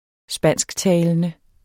Udtale [ -ˌtæːlənə ]